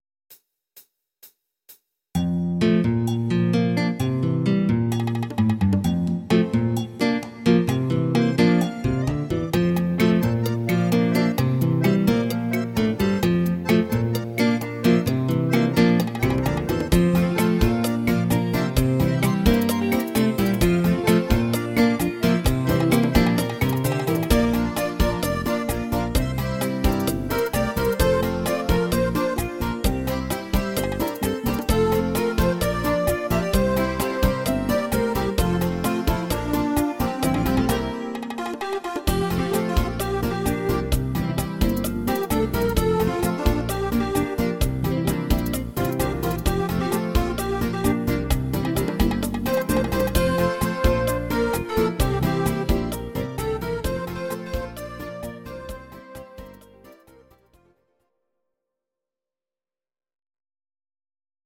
These are MP3 versions of our MIDI file catalogue.
Please note: no vocals and no karaoke included.
Salsa bachata version